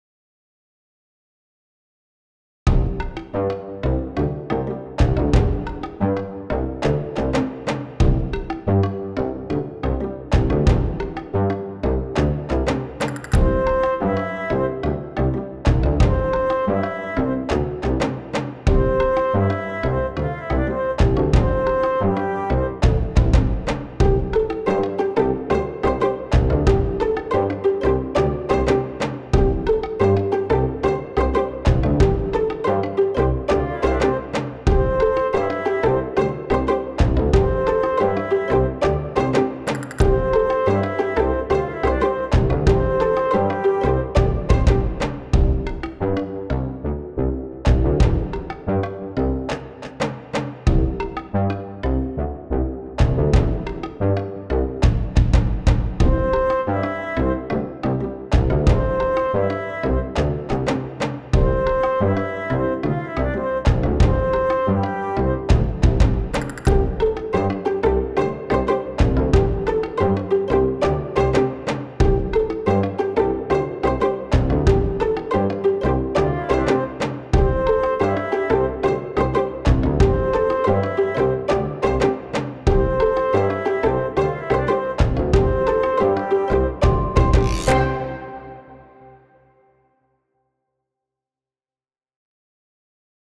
ショート明るい民族